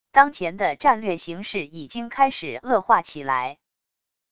当前的战略形势已经开始恶化起来wav音频生成系统WAV Audio Player